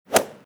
Golf Hit.m4a